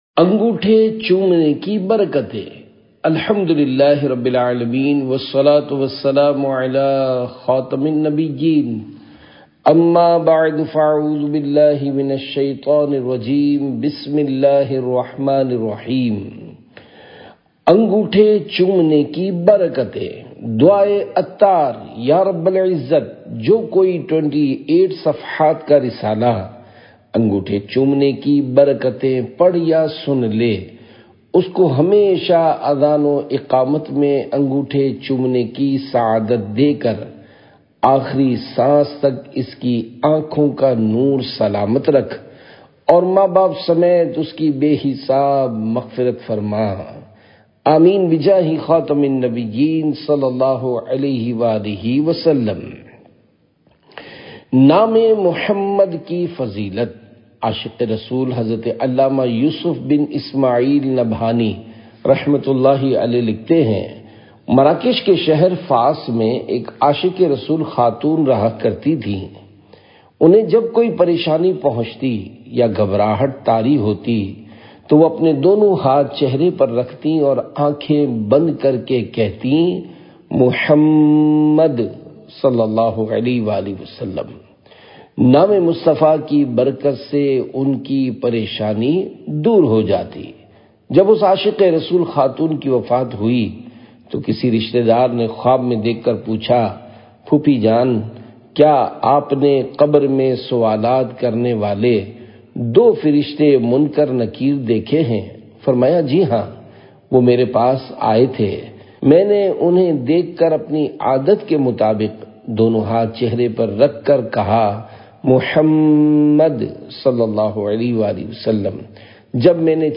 Audiobook – Angothay Chomnay Ki Barkatain (Urdu)